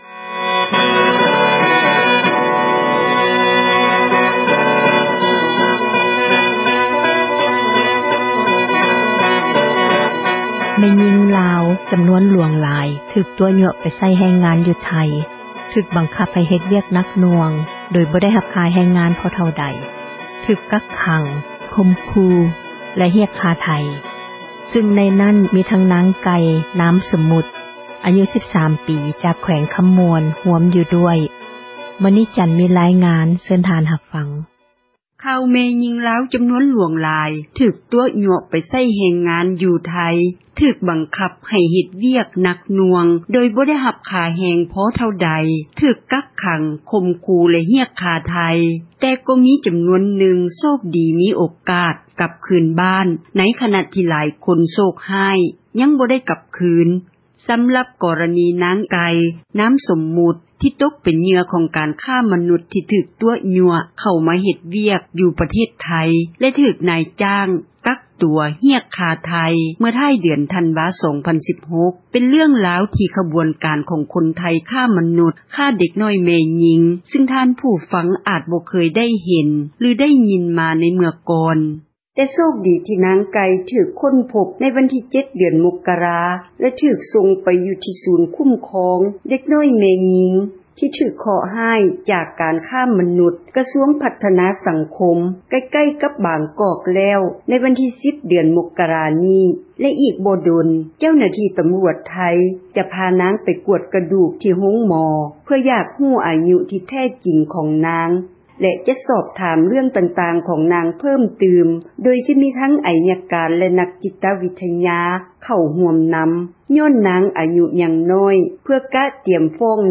ມີຣາຍງານ ເຊີນທ່ານ ຮັບຟັງ.